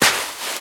STEPS Sand, Walk 21.wav